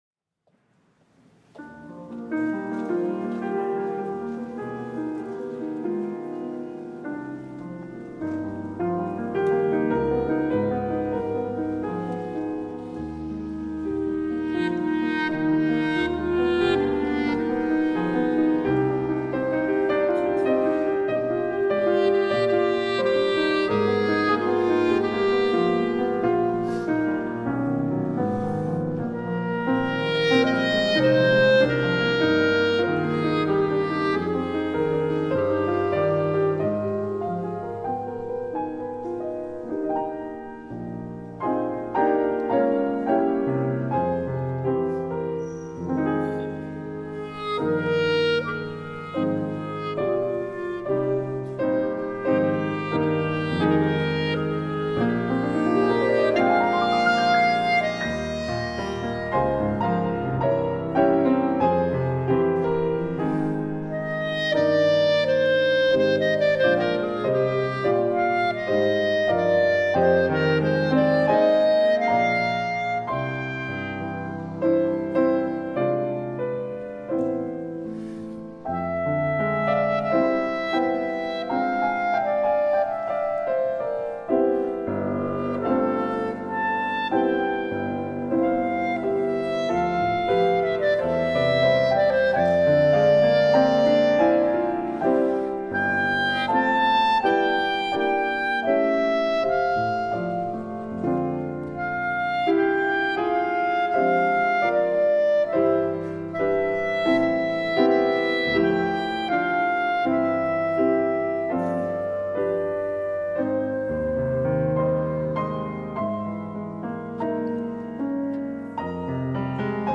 A lyrical, rhapsodic setting of the hymn
Played here by clarinetist
pianist